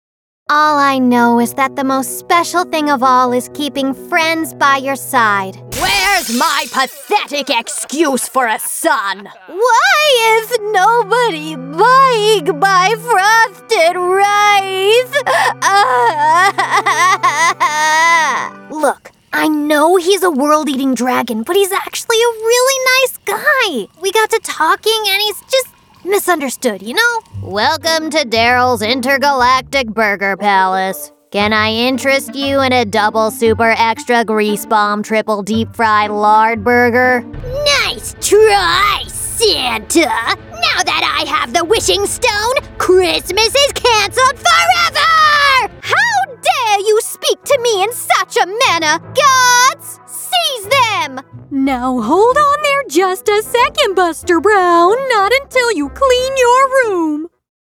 English (American)
Animation